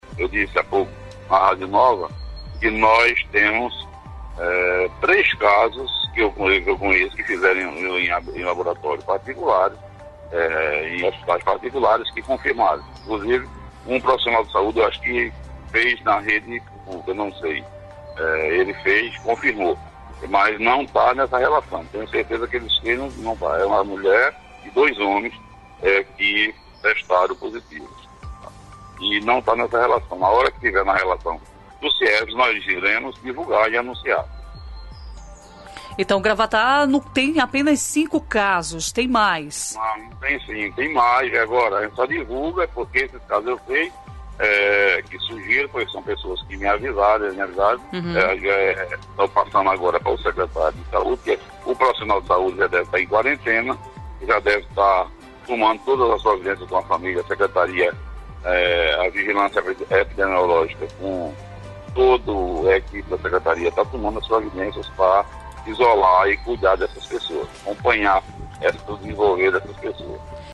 O prefeito de Gravatá, Joaquim Neto (PSDB), divulgou ao vivo na Rádio Clima FM (98.5 MHz) que além dos 05 casos confirmados do coronavírus, outros novos 03 casos podem ser adicionados ao relatório oficial nas próximas horas.